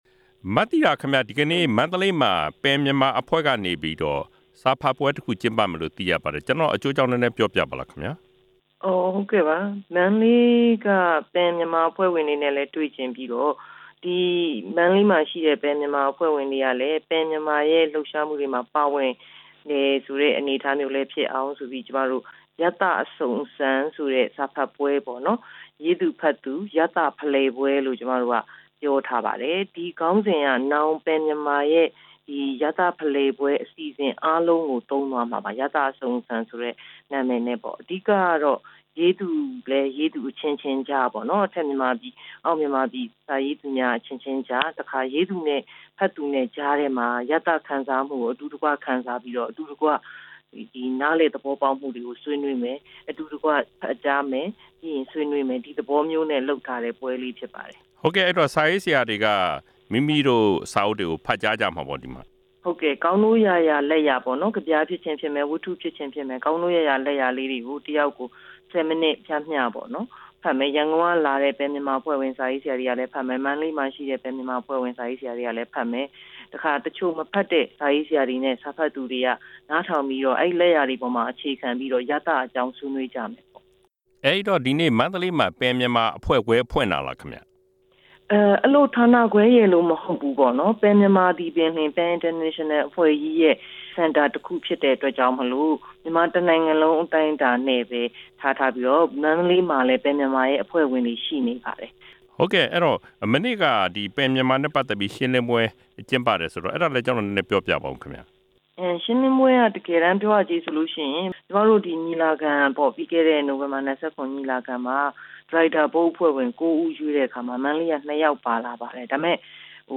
စာရေးဆရာ မသီတာ စမ်းချောင်းနဲ့ မေးမြန်းချက်
စာရေးဆရာ မသီတာ စမ်းချောင်းနဲ့ ဆက်သွယ်မေးမြန်းထားတာကို နားဆင်ပါ။